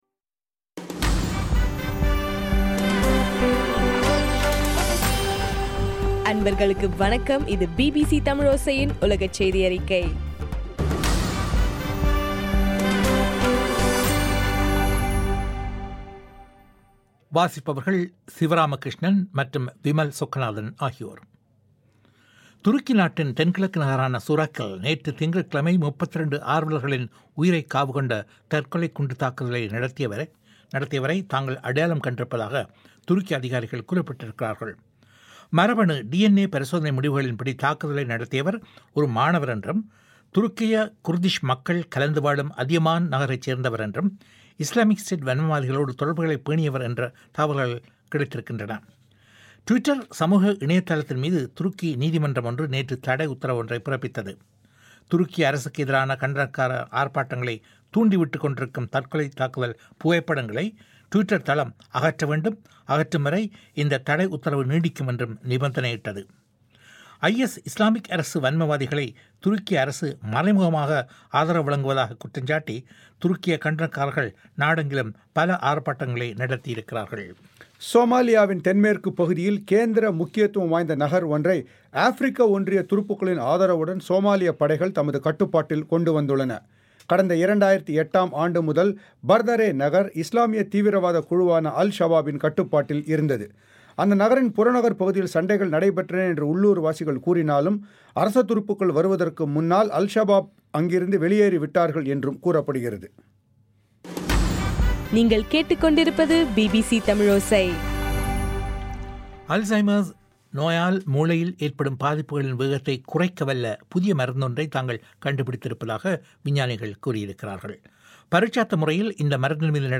ஜூலை 22 பிபிசியின் உலகச் செய்திகள்